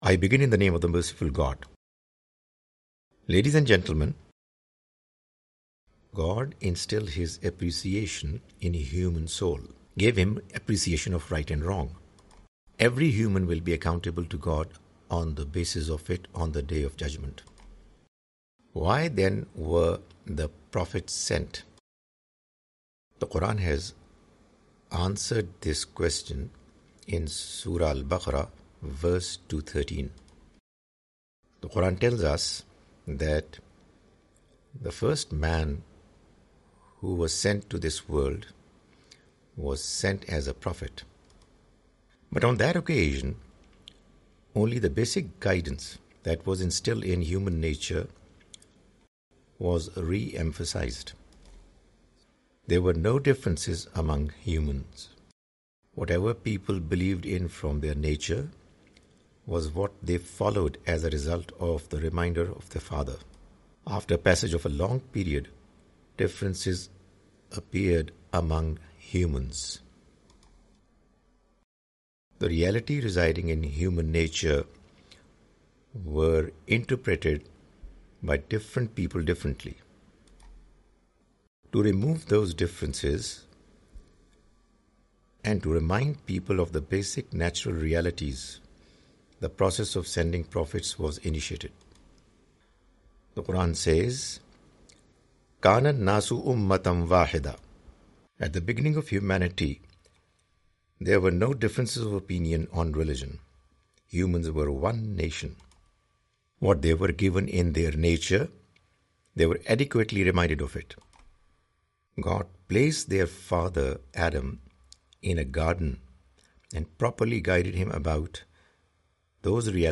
The Message of Qur'an (With English Voice Over) Part-4
The Message of the Quran is a lecture series comprising Urdu lectures of Mr Javed Ahmad Ghamidi.